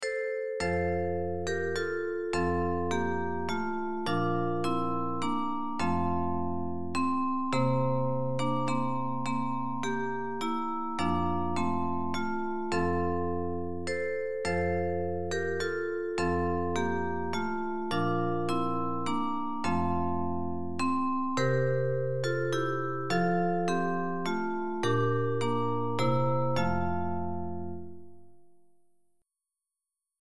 Christmas hymns